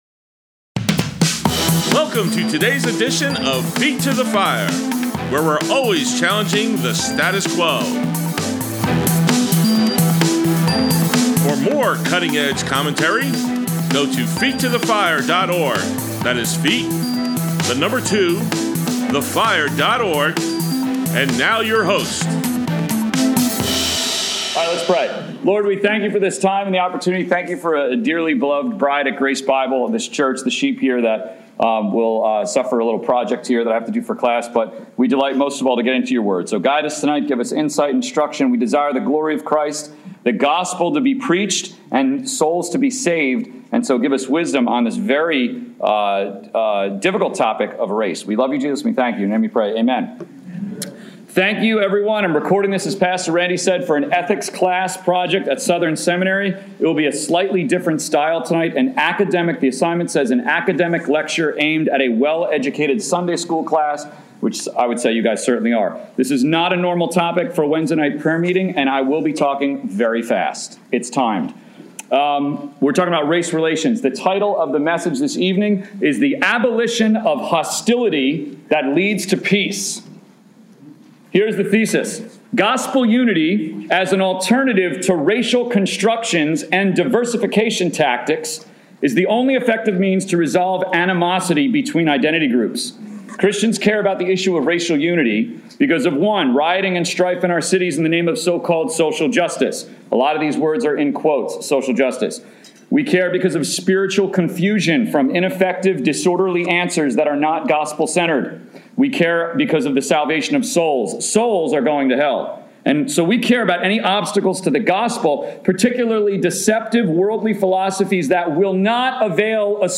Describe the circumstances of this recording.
Grace Bible Church Prayer Meeting, November 18, 2020, 7 PM